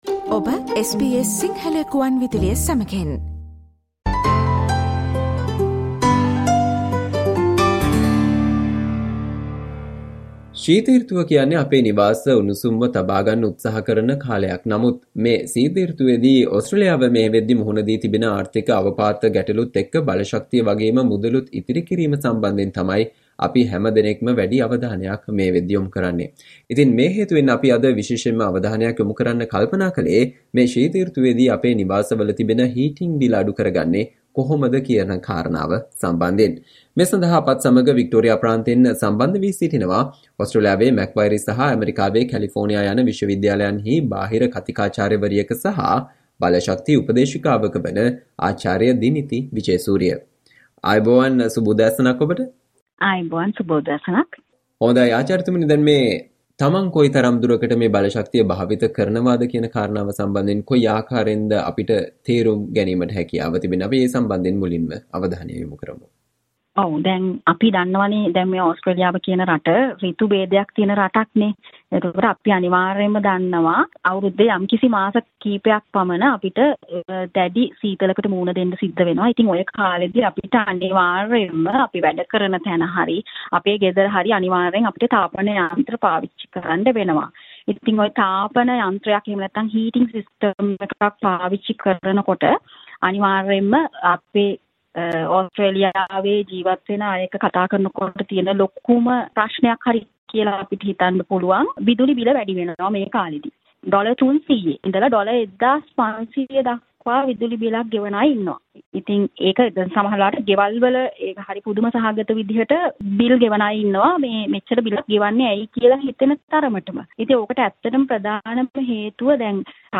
මෙම ශීත ඍතුවේ දී අපේ නිවාස වල තිබෙන Heating බිල අඩු කර ගනිමින් උණුසුම්ව නිවාස තුල සිටීමට කල හැකි දේ මොනවාද? යන්න සම්බන්ධයෙන් වන සාකච්චාවට සවන්දෙන්න